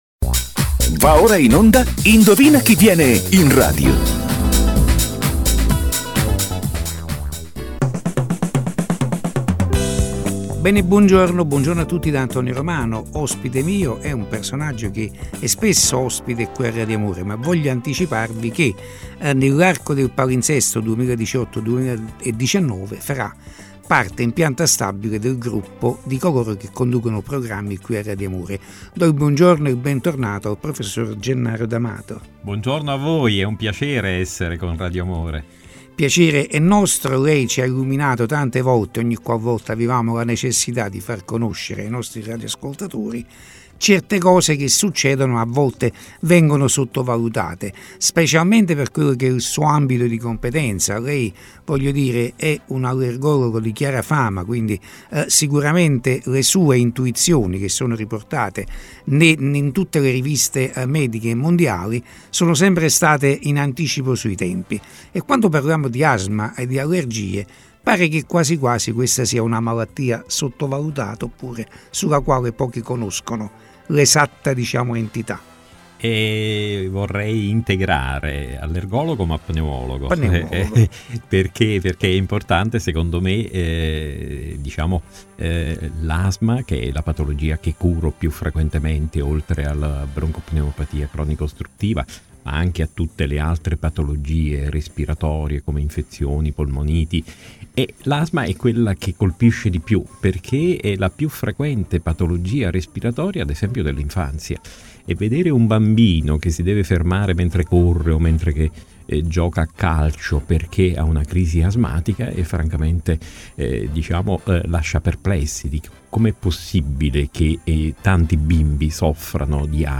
Asma bronchiale: Radio Amore intervista